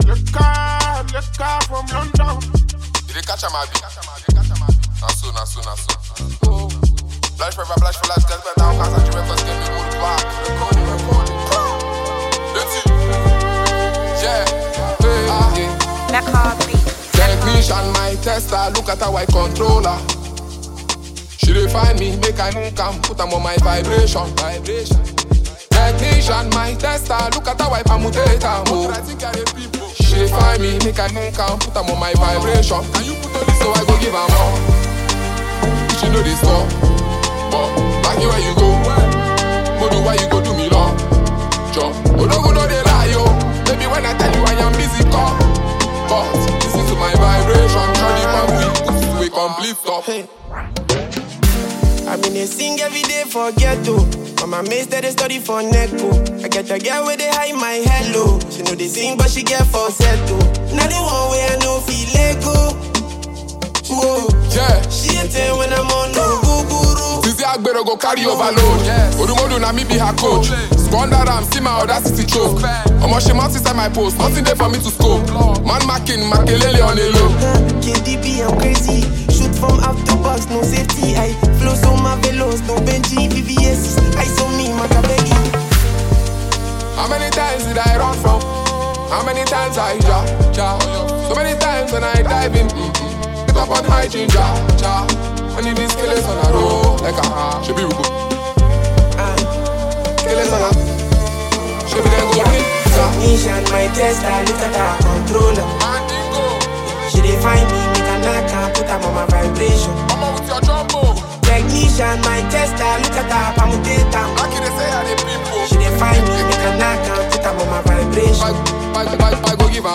Buzzing Nigerian rap sensation and songwriter